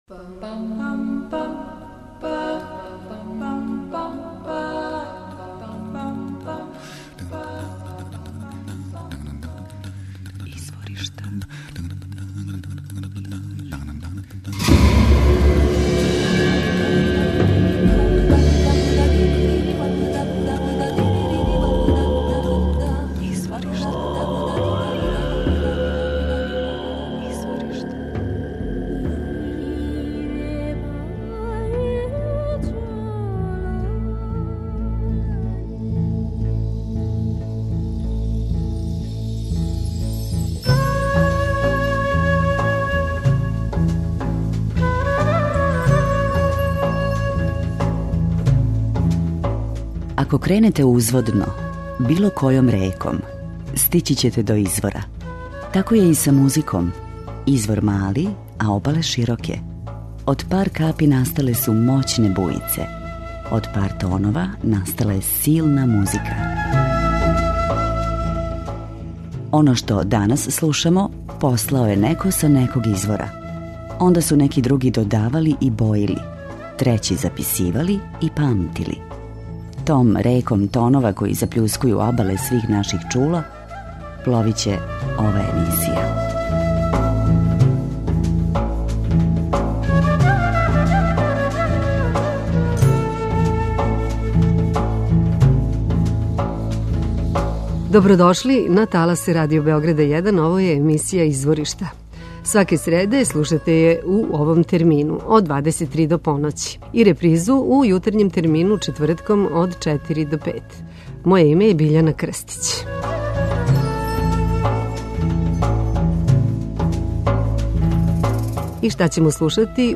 афричка блуз џез група
амерички a cappella gospel sextet
Ова вокална група повезује различите стилове R&B и Jazz.